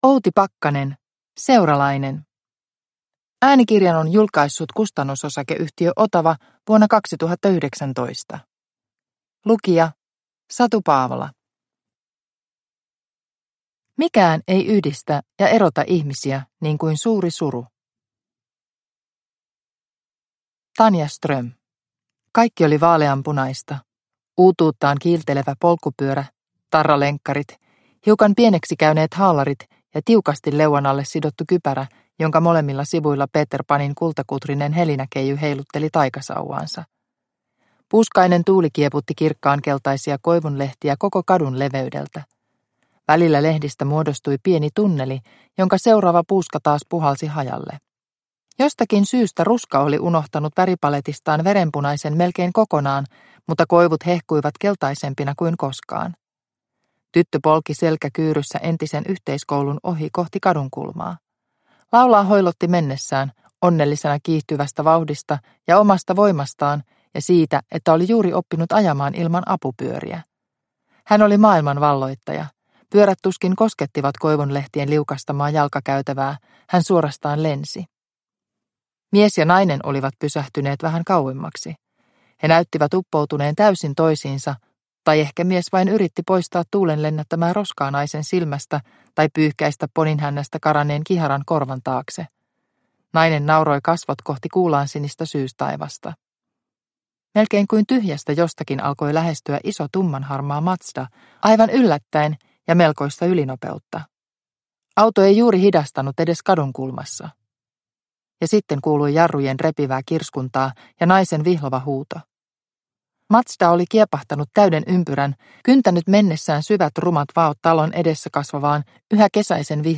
Seuralainen – Ljudbok – Laddas ner